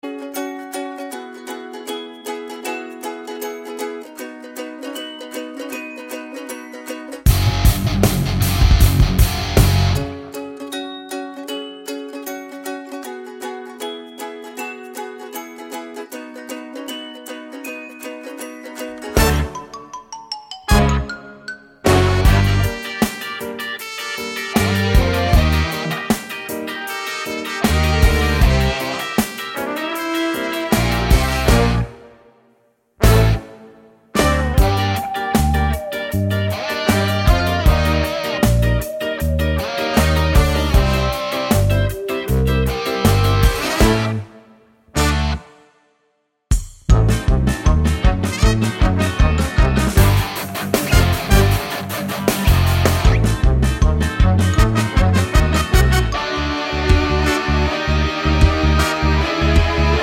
no Backing Vocals Musicals 3:43 Buy £1.50